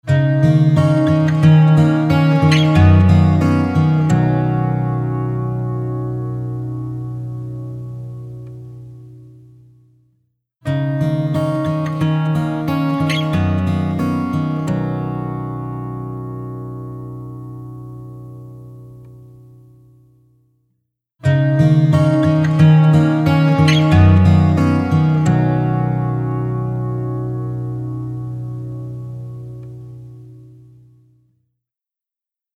TriceraChorusは、サウンドに極上の豊かさと空間的な奥行きをもたらす、洗練されたコーラス・ソリューションです。
TriceraChorus | Acoustic Guitar | Preset: CFO
TriceraChorus-Eventide-Acoustic-Guitar-CFO.mp3